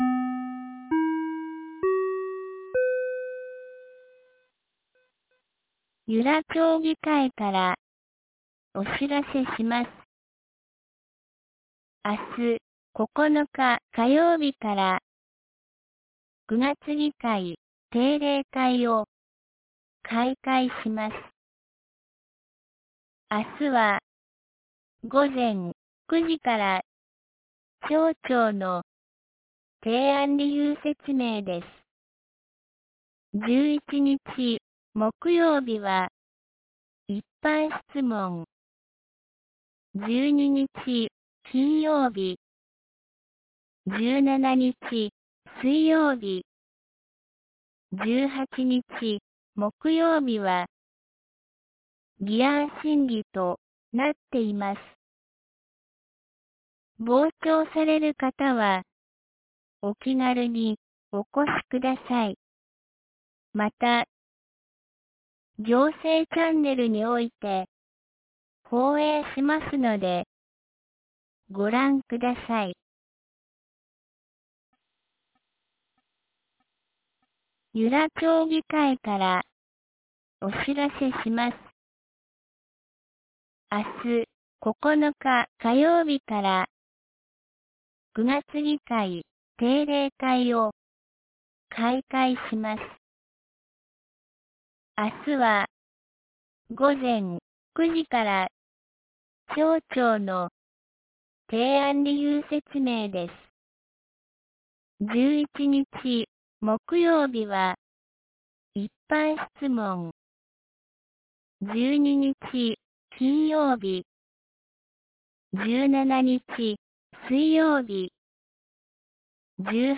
2025年09月08日 17時13分に、由良町から全地区へ放送がありました。